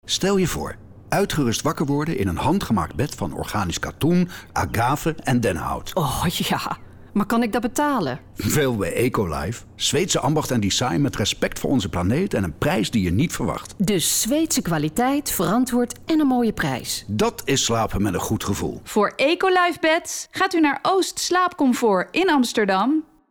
Op zoek naar een vrouwelijke voice-over met energie en overtuiging?